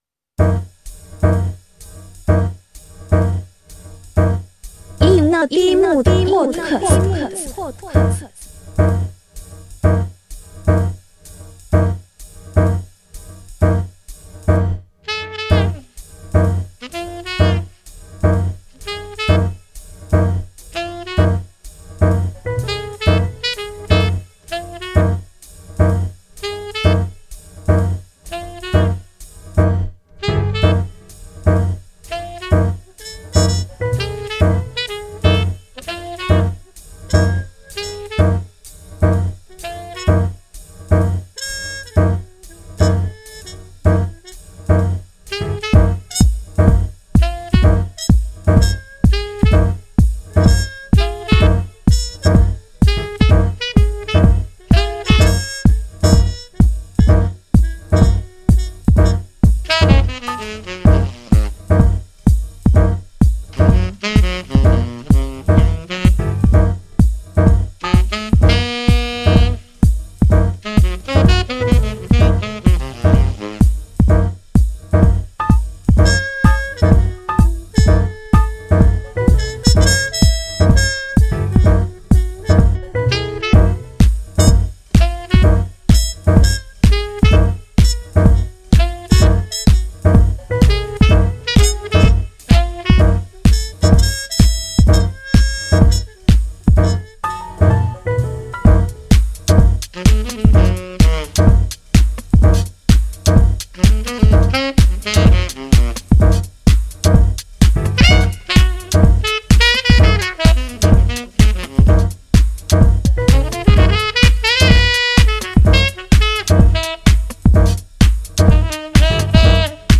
Only golden jazzy loops !